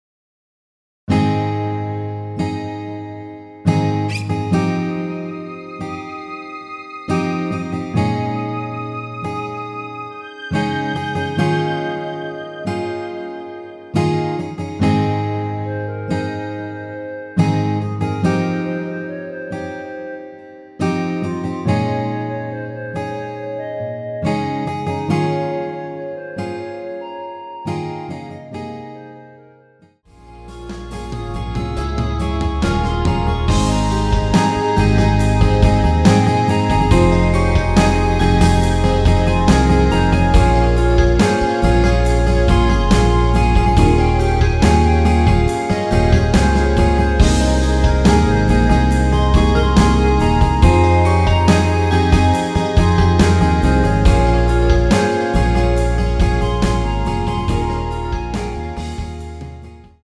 원키에서(+5)올린 멜로디 포함된 MR 입니다.
Ab
앞부분30초, 뒷부분30초씩 편집해서 올려 드리고 있습니다.
중간에 음이 끈어지고 다시 나오는 이유는